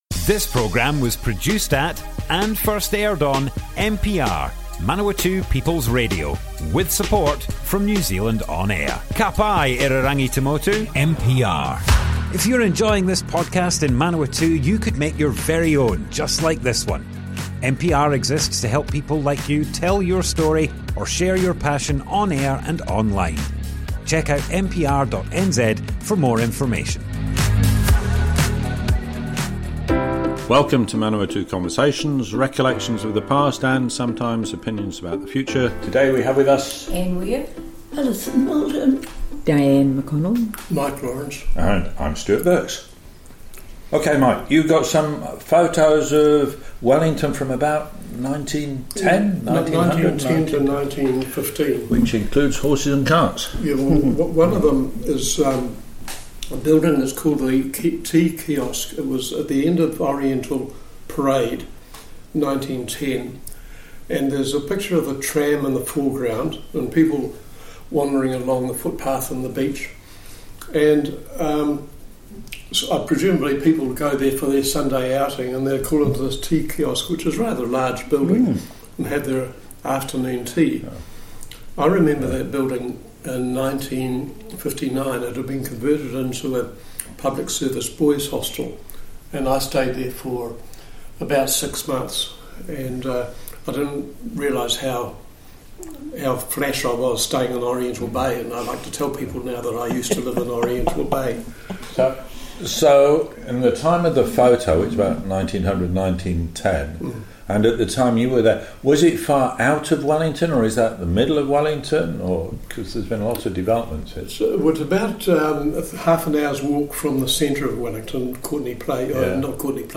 Group discussion, early experiences, Aunt Daisy - Manawatu Conversations - Manawatū Heritage
00:00 of 00:00 Add to a set Other Sets Description Comments Group discussion, early experiences, Aunt Daisy - Manawatu Conversations More Info → Description Broadcast on Manawatu People's Radio, 30th September 2025.
oral history